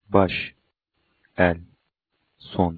In single syllable words there is no accent per se -- but the vowel is the dominent sound you hear when the word is spoken, as with...